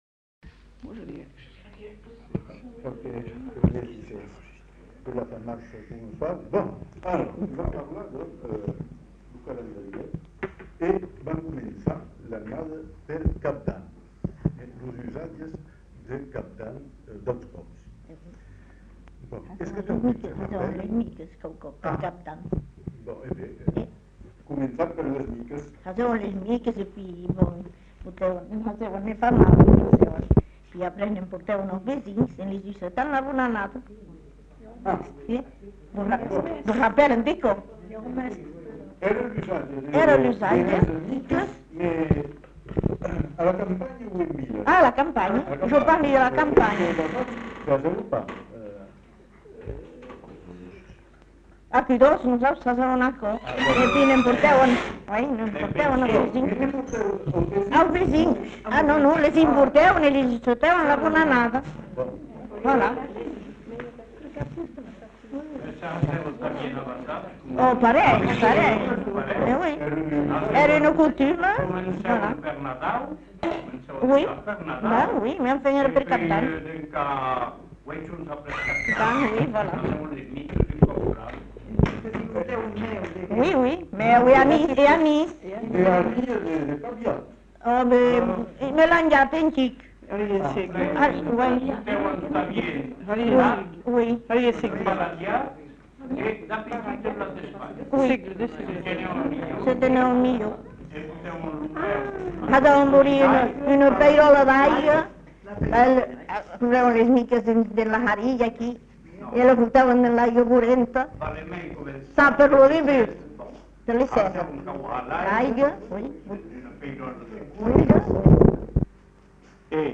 Genre : témoignage thématique
[enquêtes sonores]